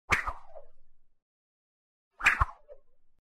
На этой странице собраны разнообразные звуки удара кнутом: от резких щелчков до протяжных свистов.
7. Взмахи хлыстом